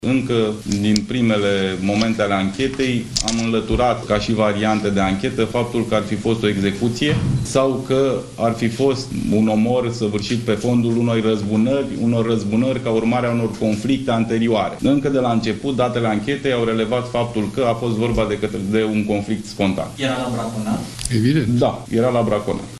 Procurorul Mihai Dincă spune că presupusul asasin era la braconat când i-a împușcat pe cei doi.